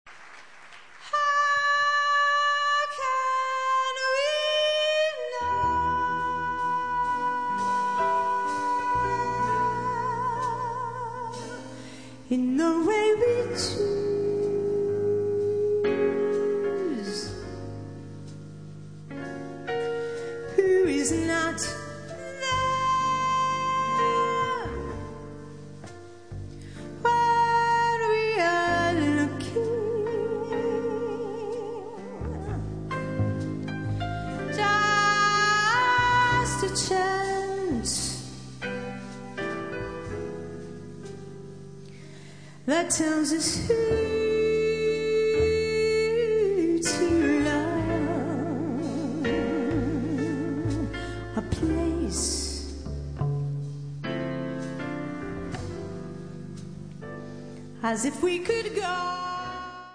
romanzo con pianoforte jazz
Voce
Sassofono
Piano
Chitarra
Basso
Batteria
accompagnata da un buon ensemble di musicisti toscani